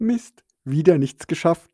amused.wav